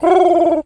pigeon_call_calm_01.wav